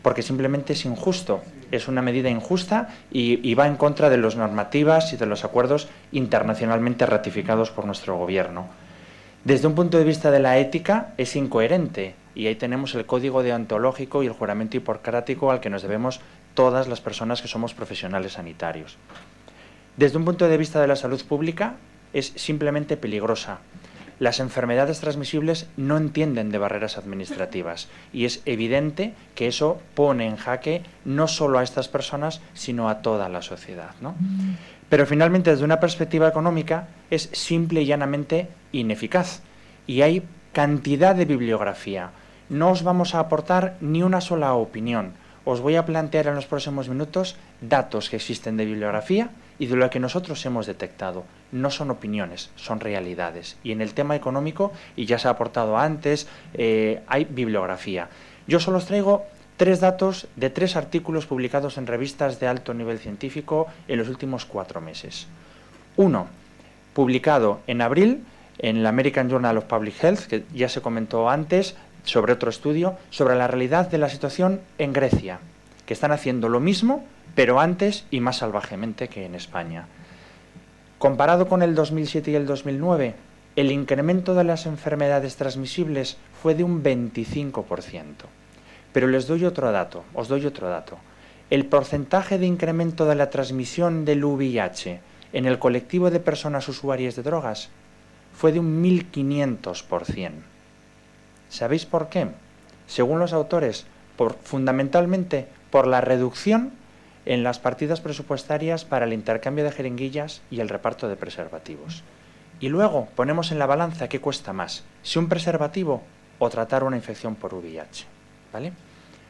Jornada sonre un año de recortes en sanidad. Congreso de los Diputados 11/10/2013